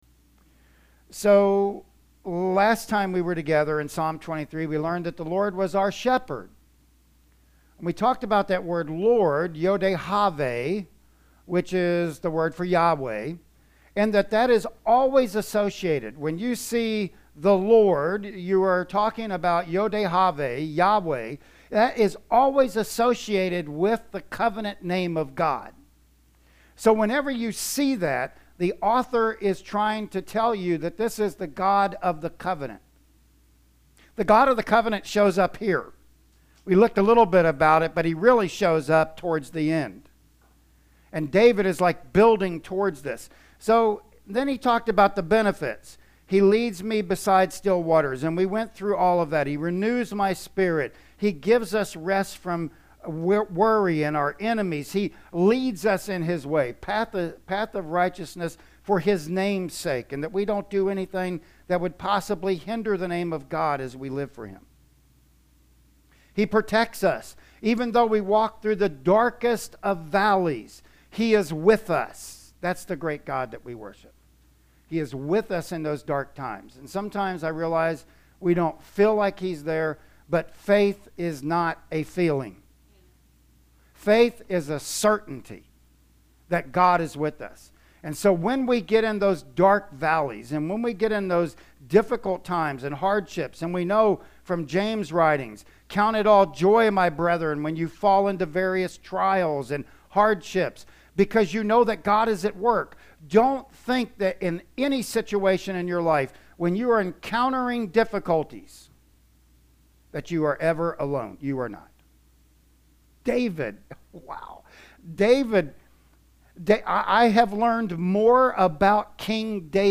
Service Type: Sunday Morning Worship Service